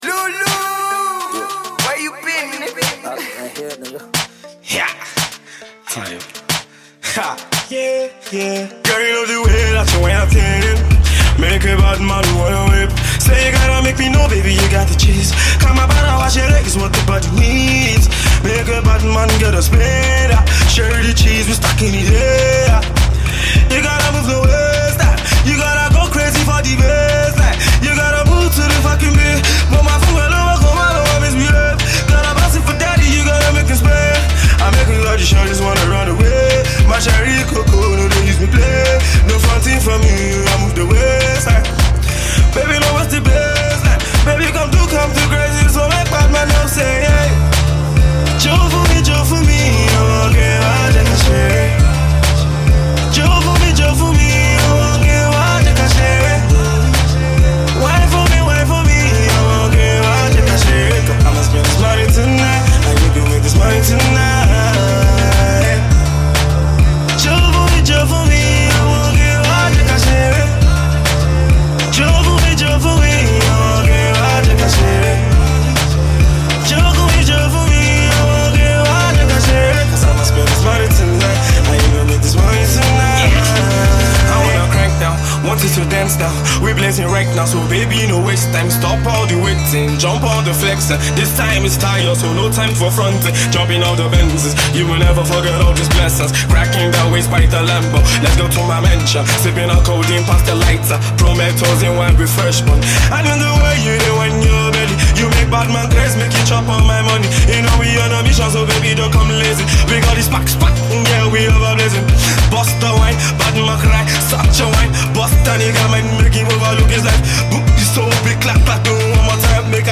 Music Genre: RnB/Trap